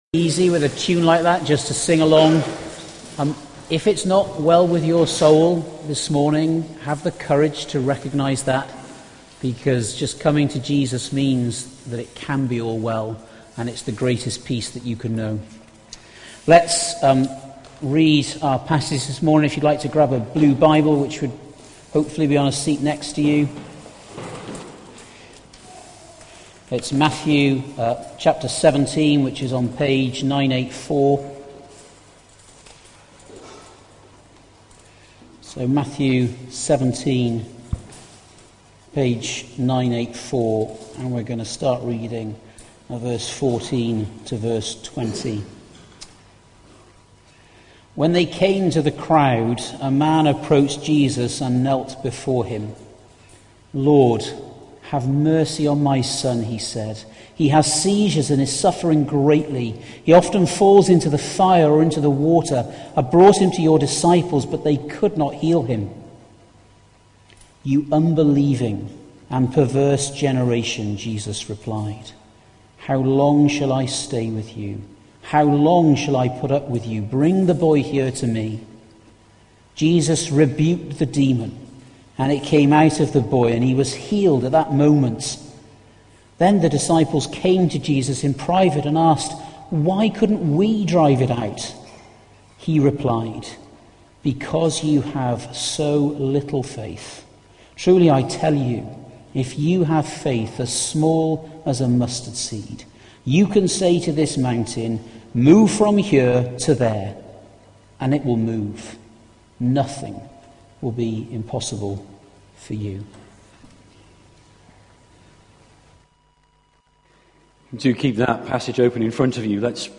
Sermons - Kensington